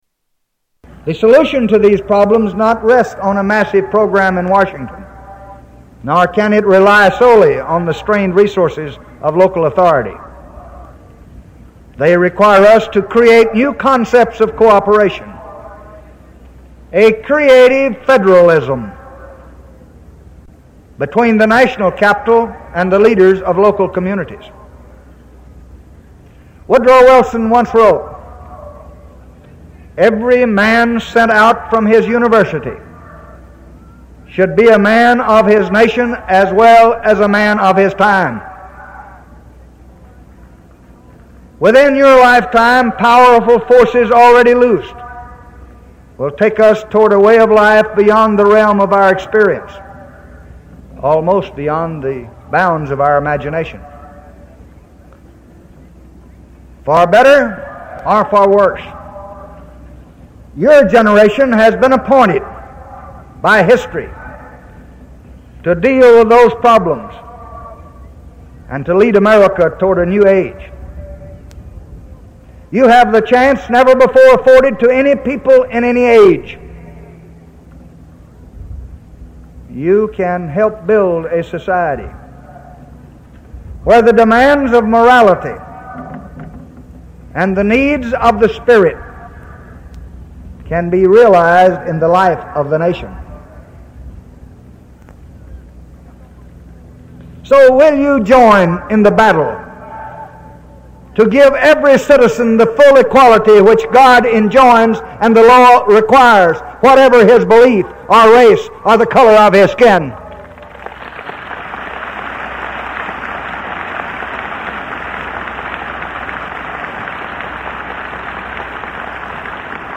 Tags: Historical Lyndon Baines Johnson Lyndon Baines Johnson clips LBJ Renunciation speech